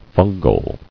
[fun·gal]